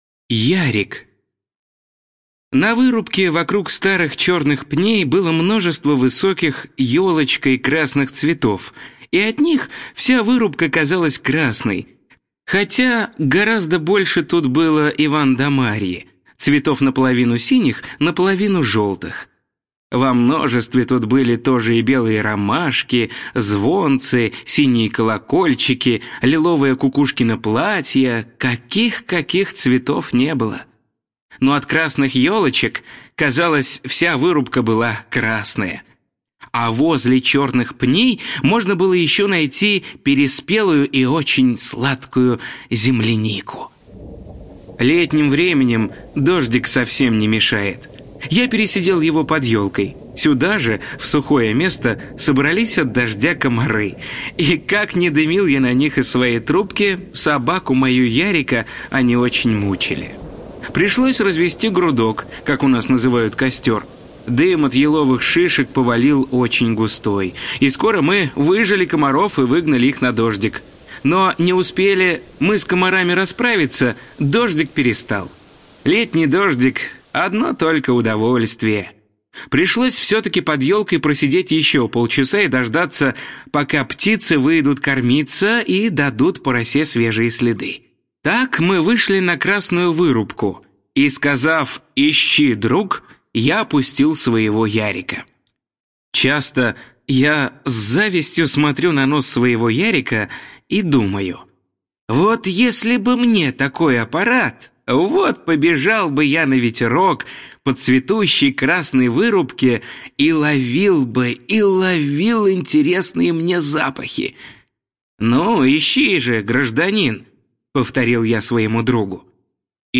Ярик - аудио рассказ Пришвина М.М. В рассказах М.Пришвина о людях и животных есть прекрасные описания лесов и полей, рек и озёр.